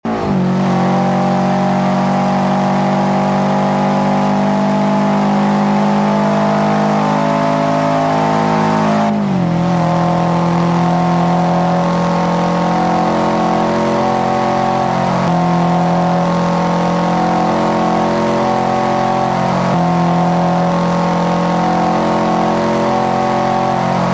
Index of /server/sound/vehicles/lwcars/subaru_impreza
fourth_cruise.wav